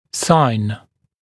[saɪn][сайн]знак, признак, симптом